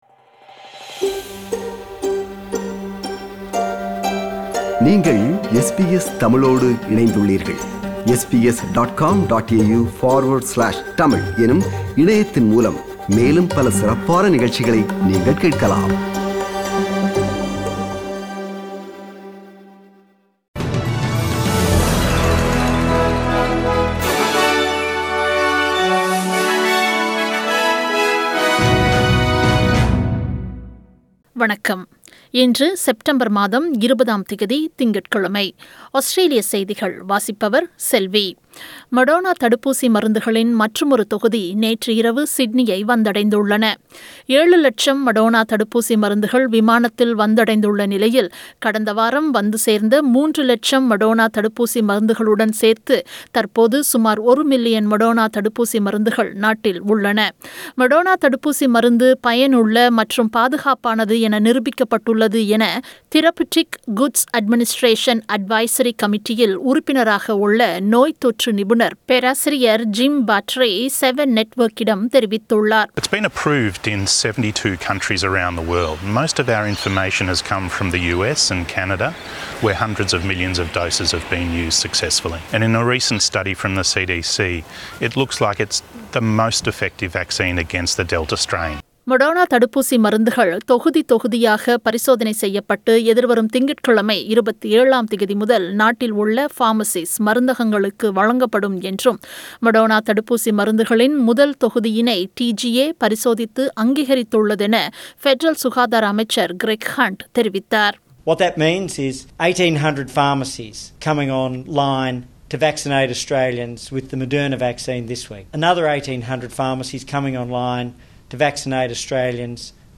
Australian News: 20 September 2021 – Monday